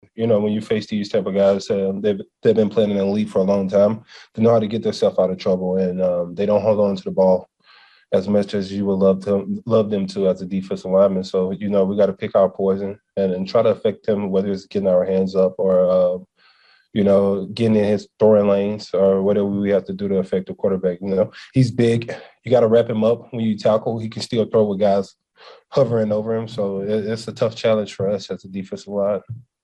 Chiefs defensive lineman Chris Jones says one of the challenges they will face tonight will be continuing Steelers quarterback Ben Roethlisberger.